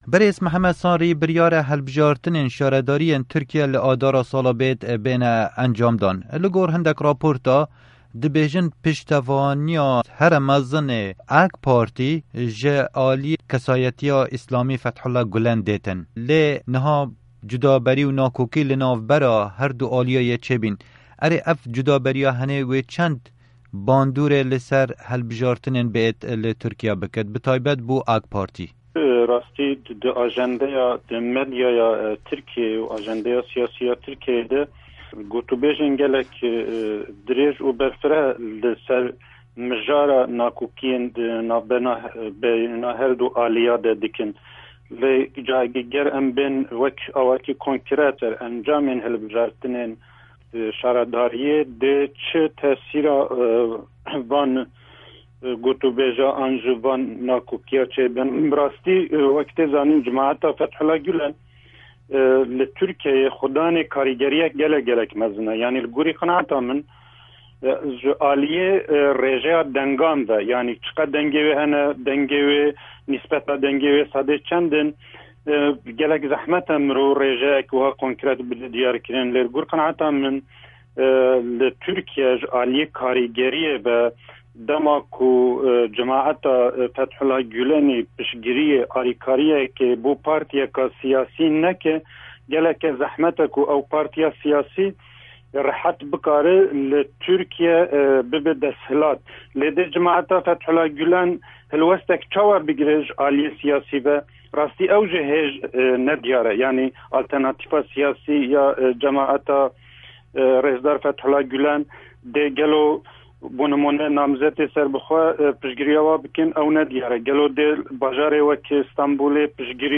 Hevpeyvîn